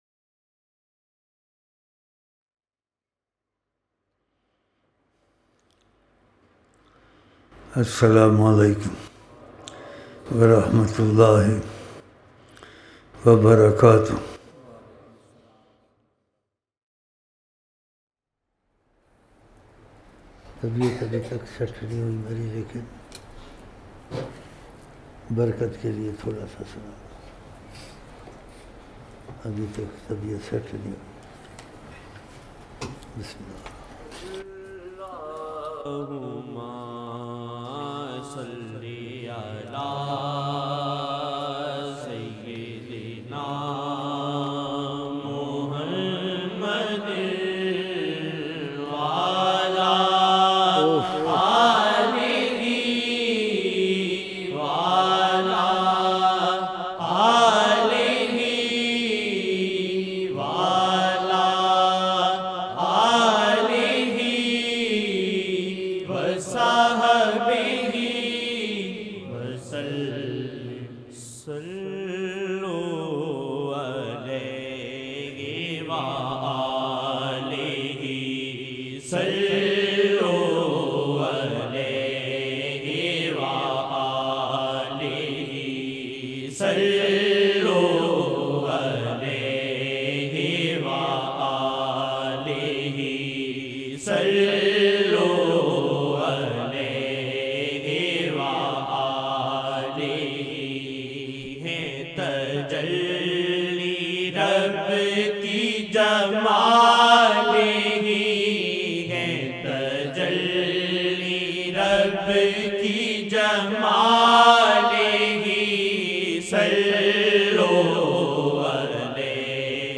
Na'at Shareef